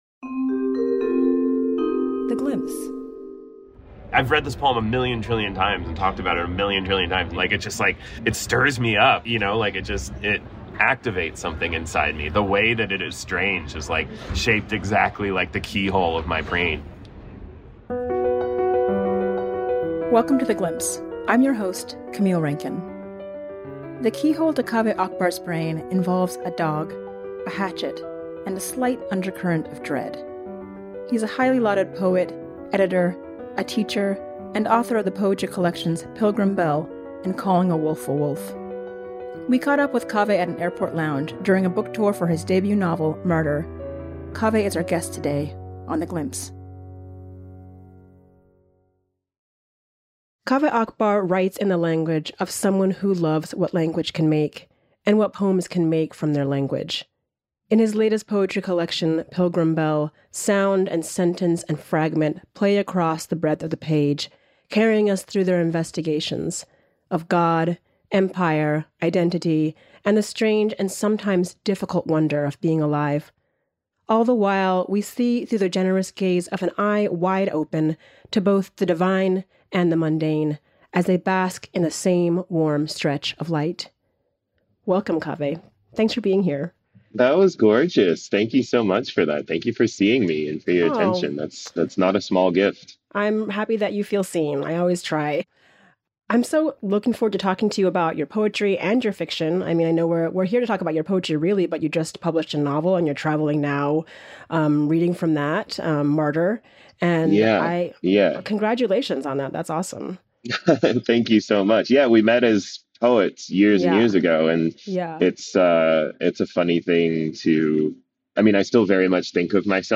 The Glimpse features lively conversations between poets about their work and about poems that inspire them.